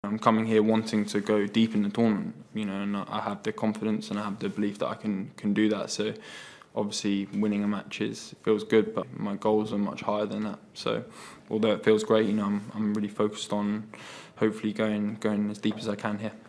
Jack Draper speaks from Roland Garos.